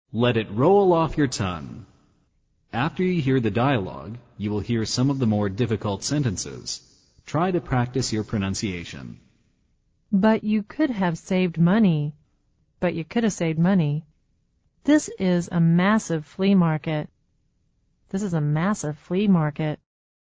《发音练习》